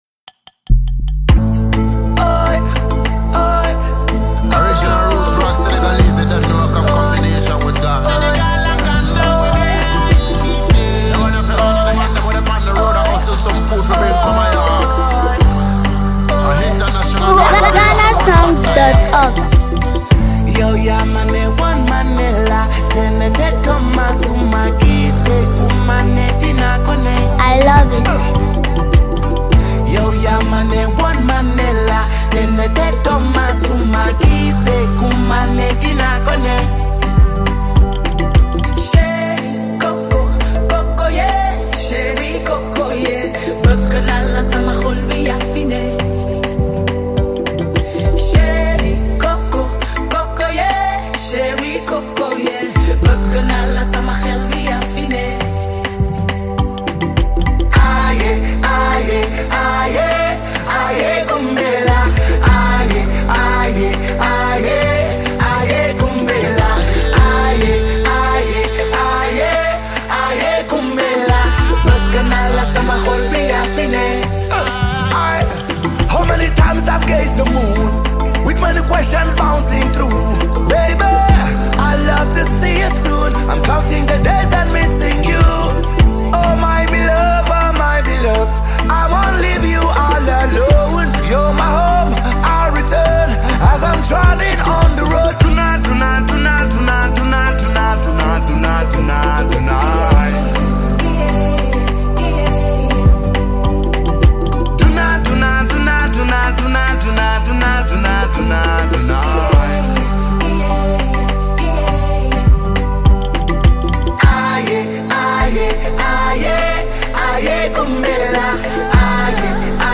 • Authentic reggae rhythms
• Soul-stirring vocals
• African and Caribbean musical fusion